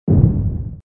detonation_ball02.wav